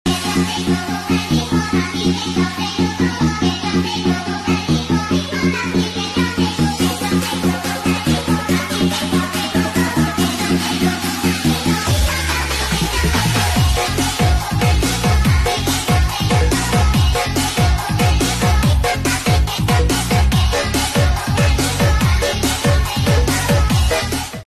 Leher knalpot jms ORIGINAL logo